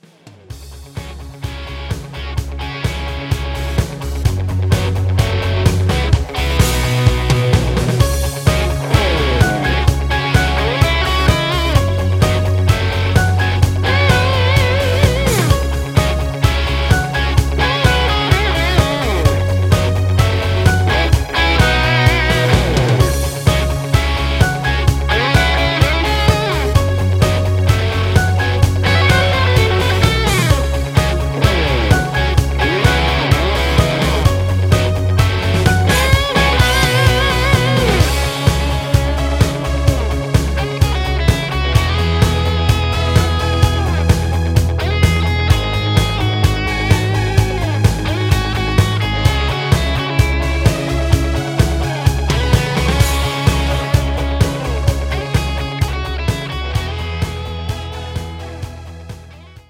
Instrumental
Singing Calls